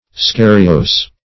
Search Result for " scariose" : The Collaborative International Dictionary of English v.0.48: Scariose \Sca"ri*ose\, Scarious \Sca"ri*ous\, a. [F. scarieux, NL. scariosus.